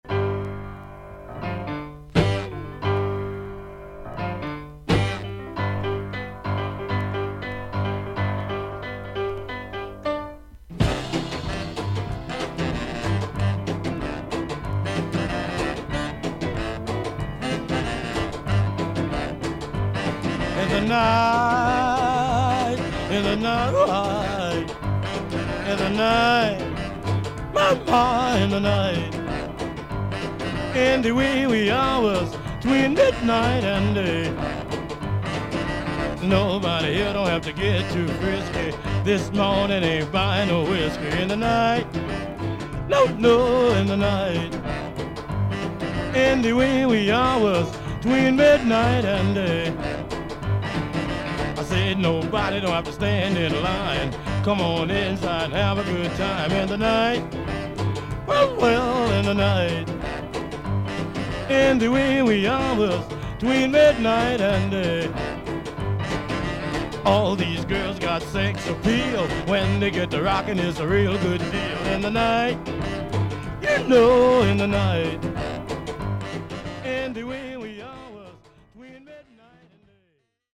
アメリカ盤 / 12インチ LP レコード / ステレオ盤
少々軽いパチノイズの箇所あり。クリアな音です。
ニュー・オーリンズR&B/ブルース・ピアニスト。